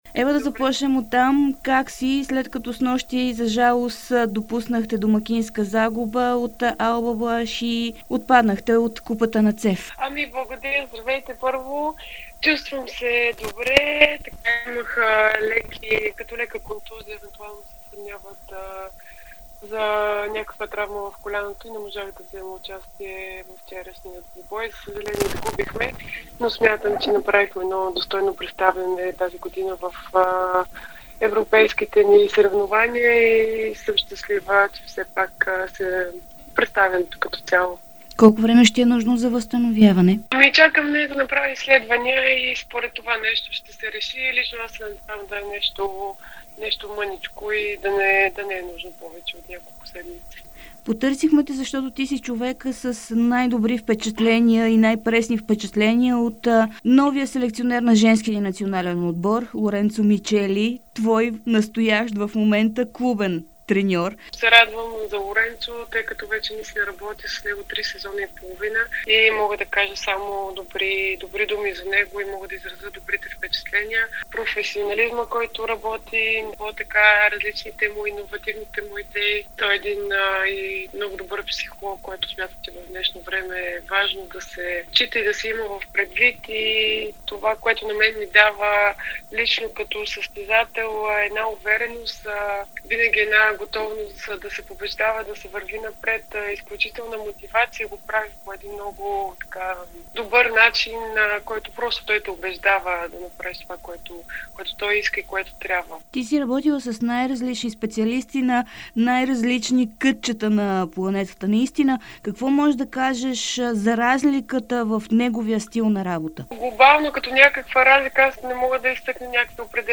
Българската волейболна националка Ева Янева даде специално интервю пред Дарик радио и dsport, в което говори за новия селекционер на женския ни национален отбор Лоренцо Мичели, който е нейн наставник и в тима на Волеро льо Кане. Това е и първи коментар на Янева по въпроса с избора на Мичели, с когото вече три години работят заедно на клубно ниво.